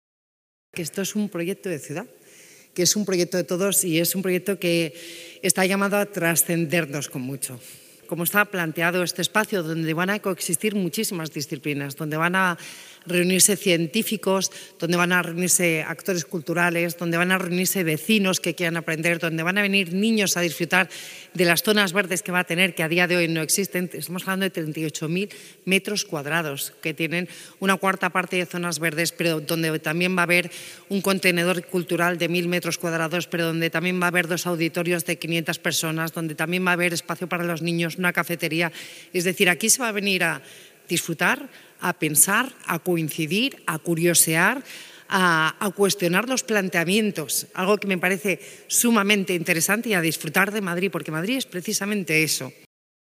Nueva ventana:Declaraciones de la vicealcaldesa, Begoña Villacís